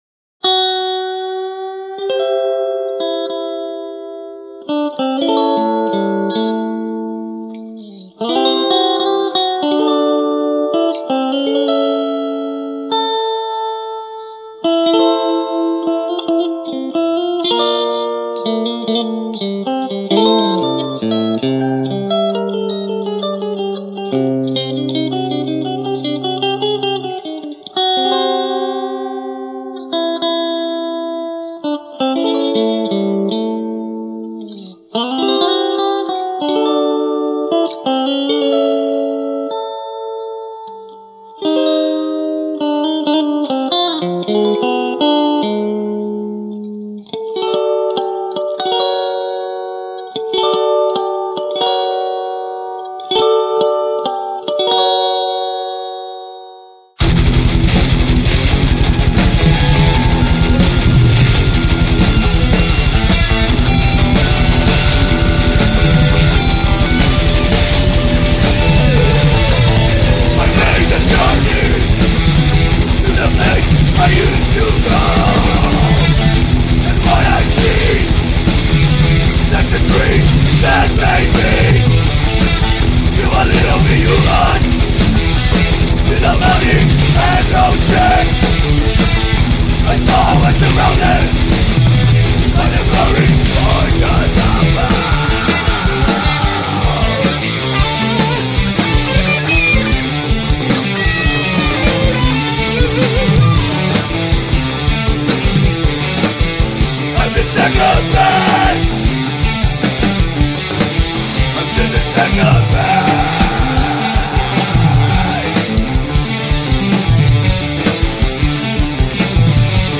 For Metal,
Guitars / Bass / Synths / Spoons
Drums
Voice
Choir